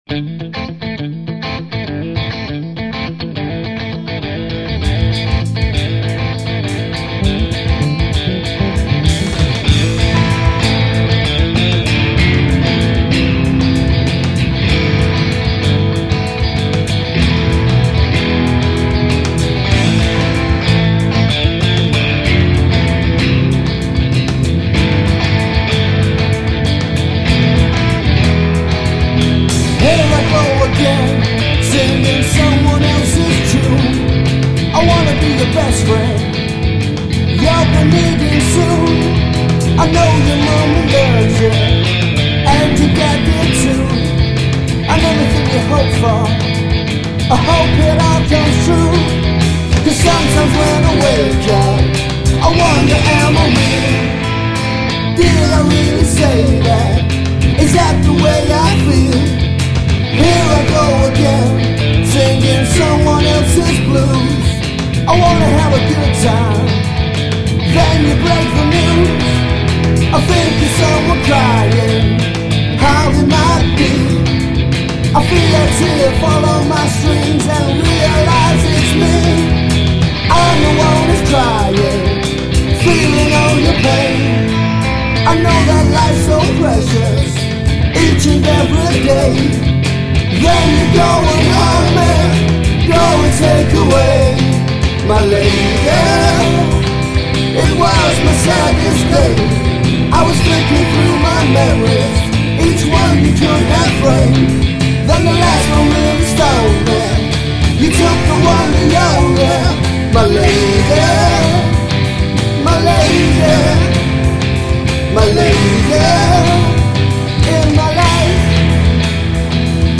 I play the bass and moan.